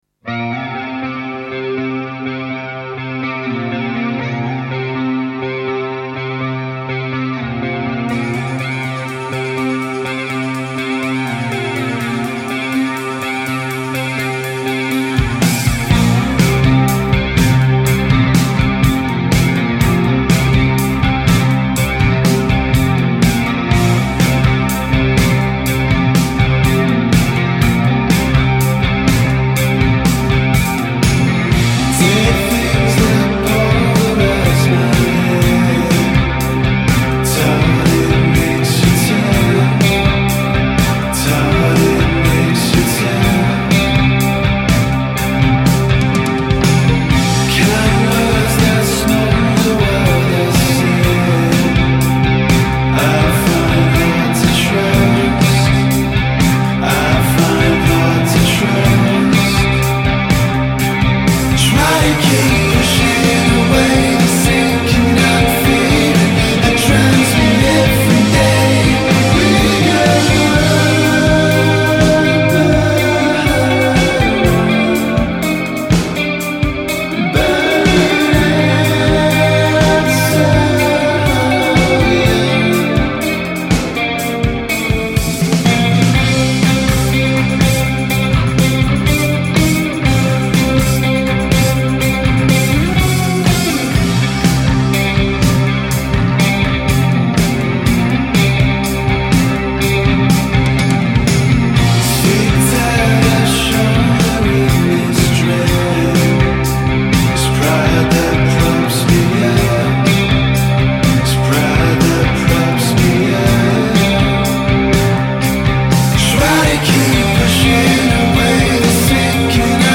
psych-rock
A dark, swirling, drifty track fuelled by attitude